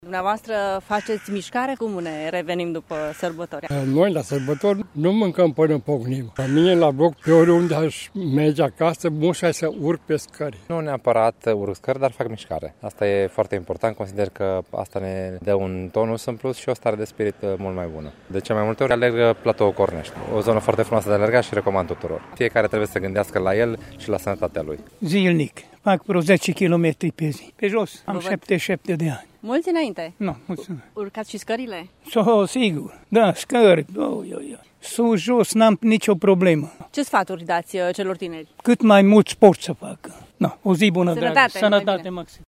Mulți târgumureșeni fac zilnic mișcare, fie că sunt tineri sau mai în vârstă: